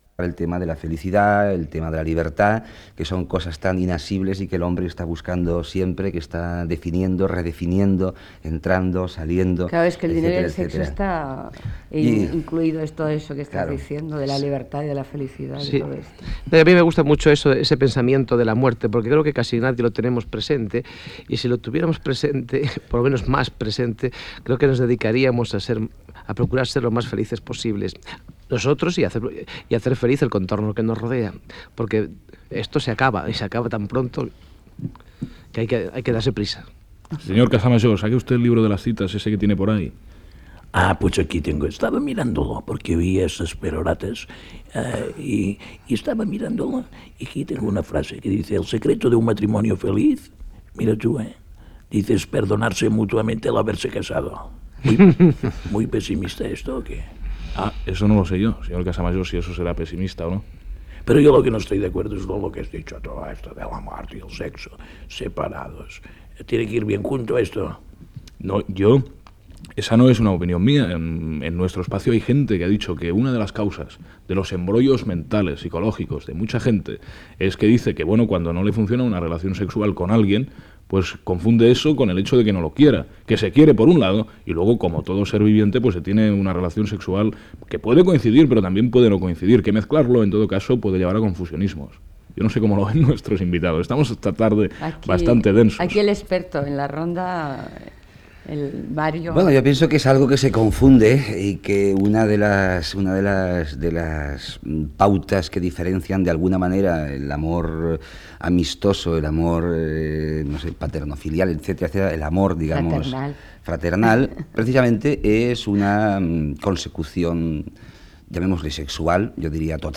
Conversa amb Mario Gas, Julieta Serrano i Manuel Galiana sobre els actors i els directors i el teatre representat a Catalunya en castellà